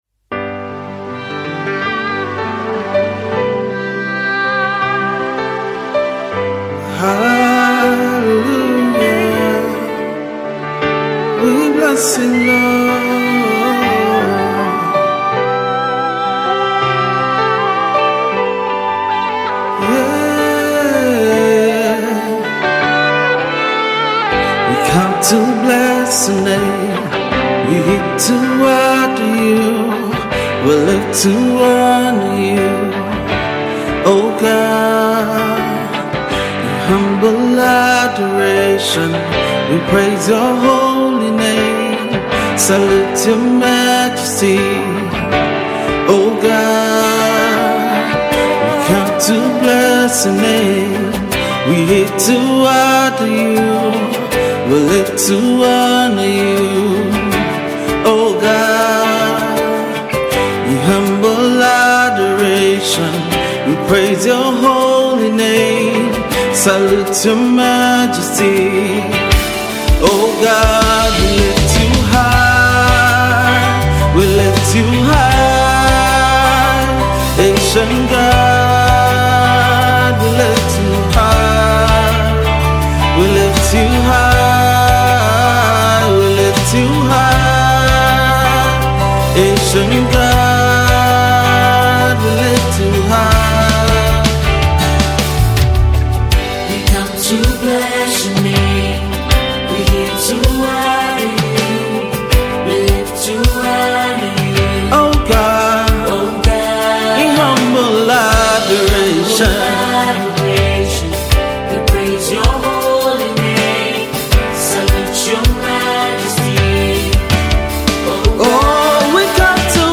delivers this beautiful contemporary song of worship